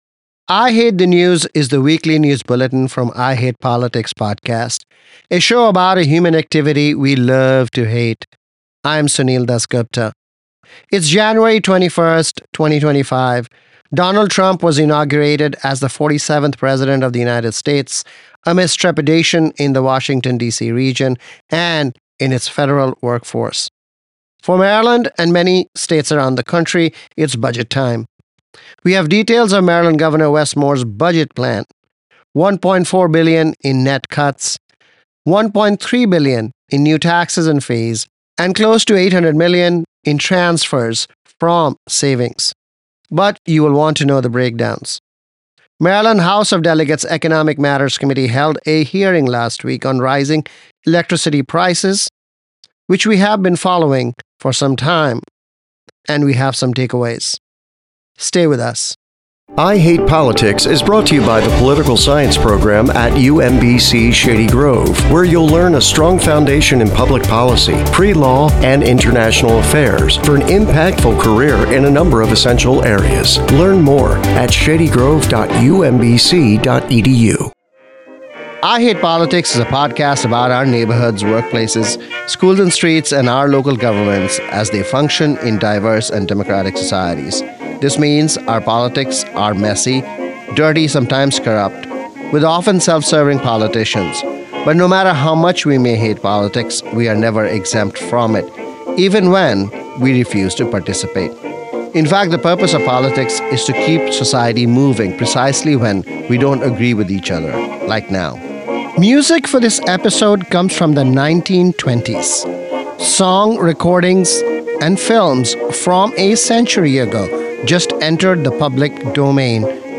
The weekly news analysis from I Hate Politics: Donald Trump is inaugurated as the 47th president of the US amidst fears in blue states like Maryland where hundreds of thousands of federal workers and contractors live and work. Maryland Governor Wes Moore presents a balanced budget for 2025 with $1.4 bn in cuts, $1.3 bn new revenue, and $800 m in transfers from savings.